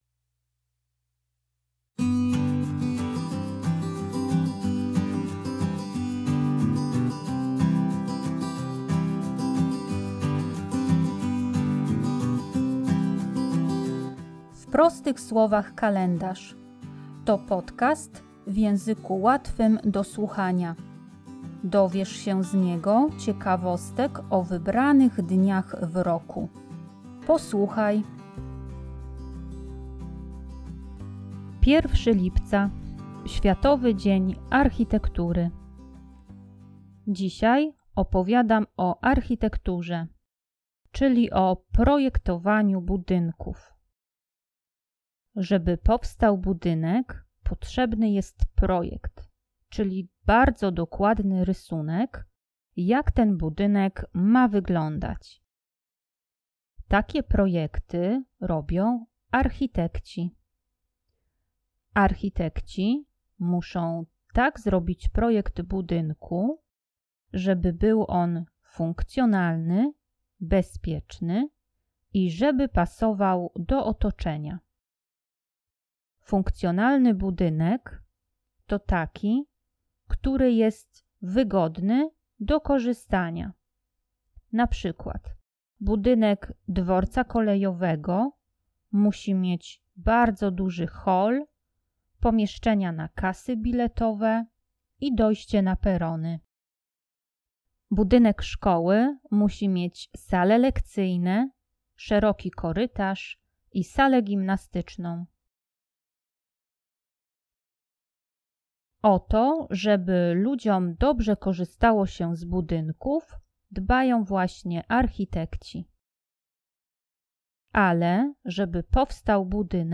Podcast w języku łatwym do słuchania - odcinek 31